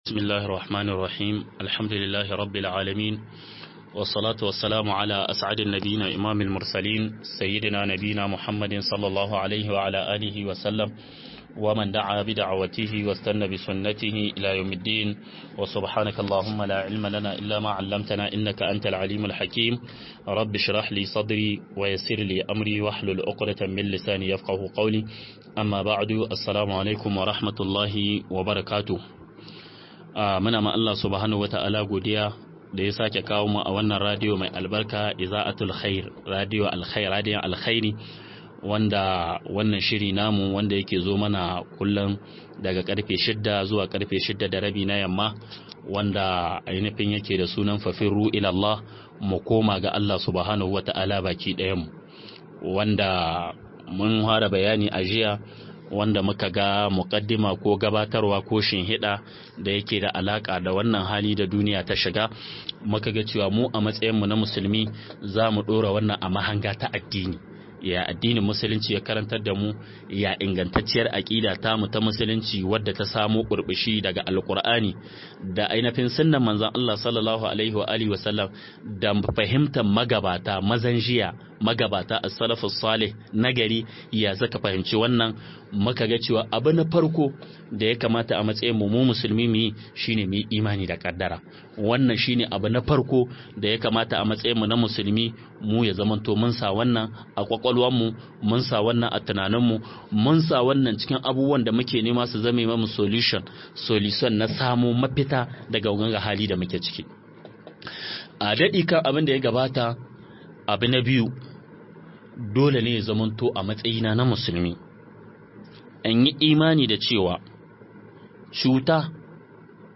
MUKOMA-ZUWA-GA-ALLAH-SHINE-MAFITA-02 - MUHADARA